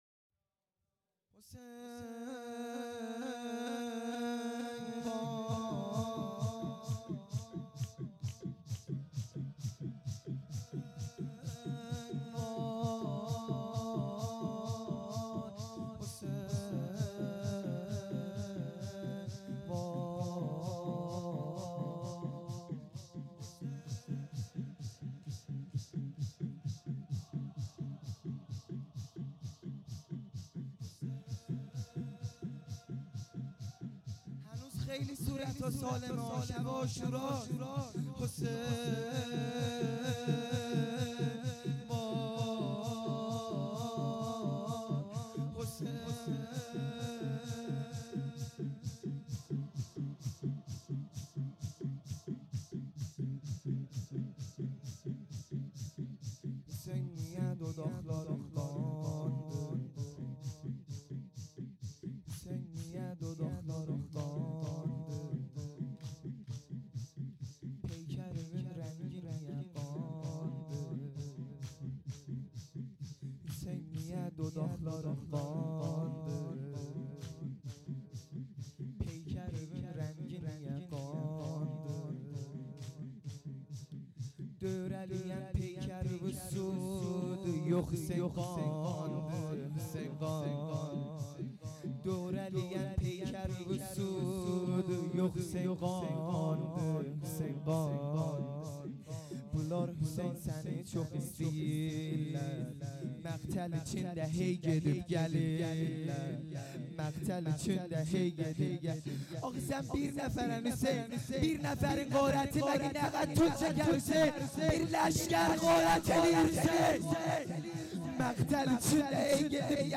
لطمه زنی